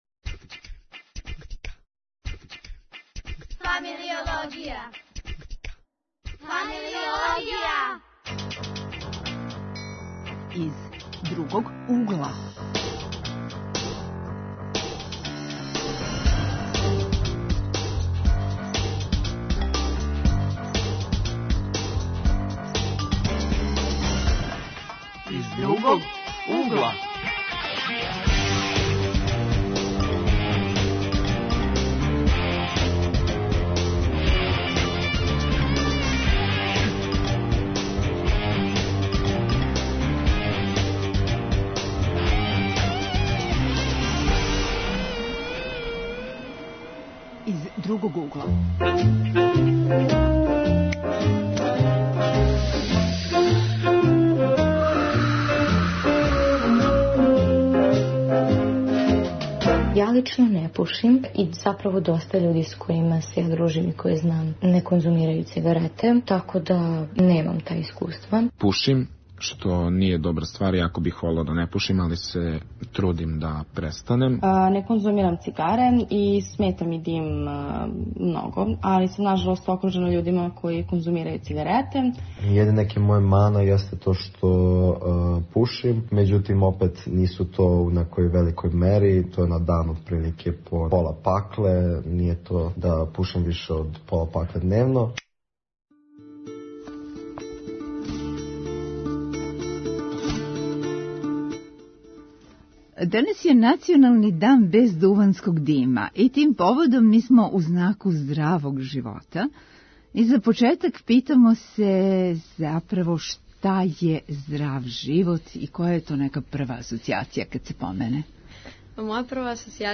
Гости су студенти